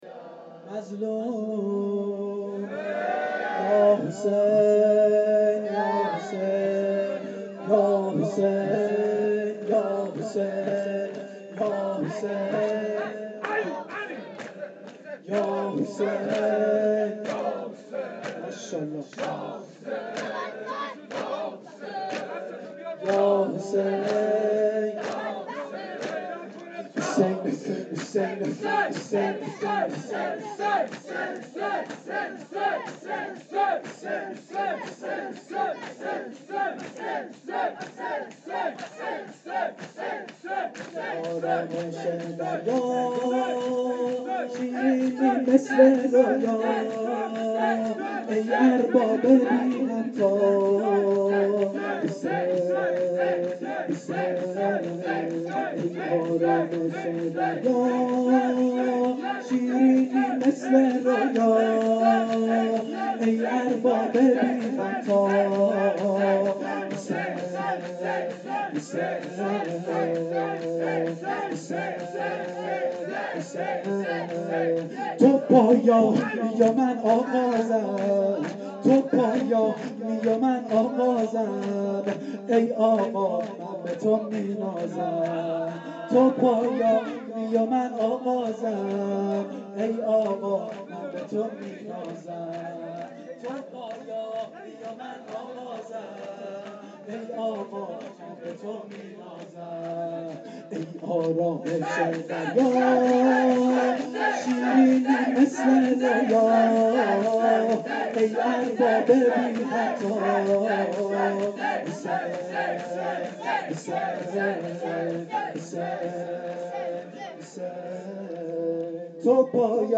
شور شب ششم محرم1393
Shoor-Shabe-06-moharram93.mp3